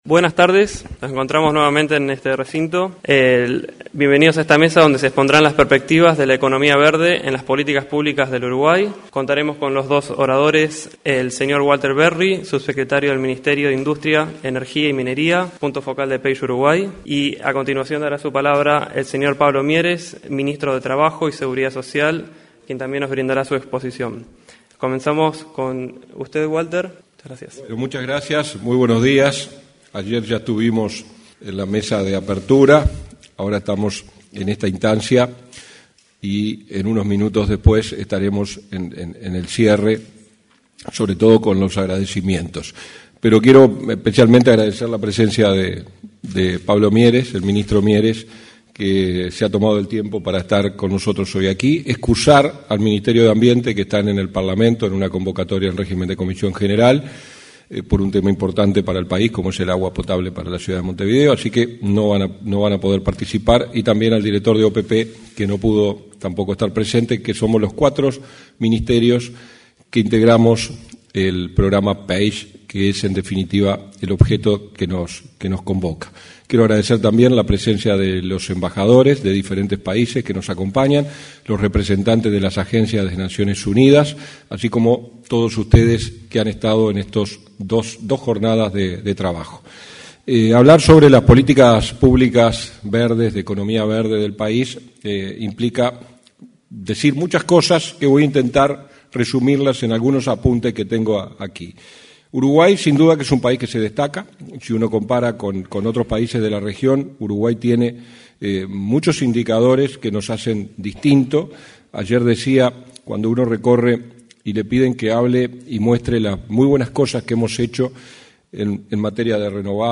Palabras de autoridades en encuentro Aprendizajes y Perspectivas de la Economía Verde Inclusiva
Palabras de autoridades en encuentro Aprendizajes y Perspectivas de la Economía Verde Inclusiva 19/10/2022 Compartir Facebook X Copiar enlace WhatsApp LinkedIn Este miércoles 19 de octubre se realizó el encuentro Aprendizajes y Perspectivas de la Economía Verde Inclusiva. En la oportunidad, se expresaron el subsecretario de Industria, Energía y Minería, Walter Verri, y el ministro de Trabajo y Seguridad Social, Pablo Mieres.